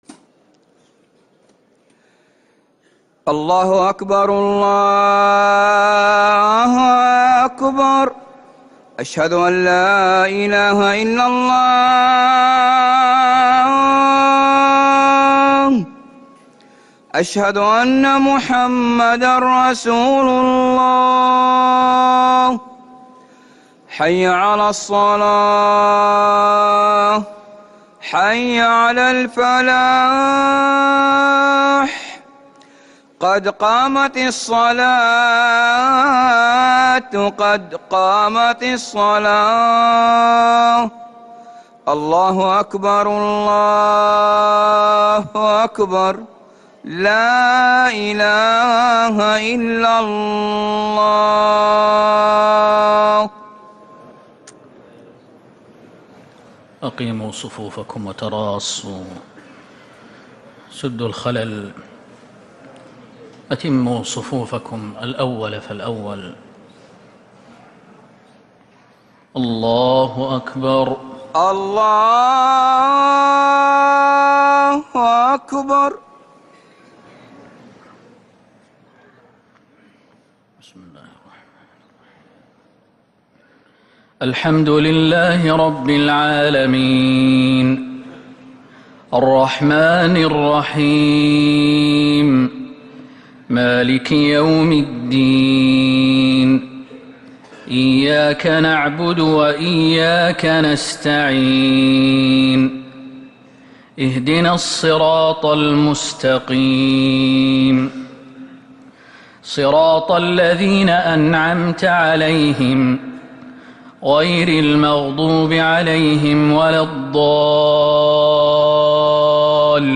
Haramain Salaah Recordings: Madeenah Maghrib - 15th January 2026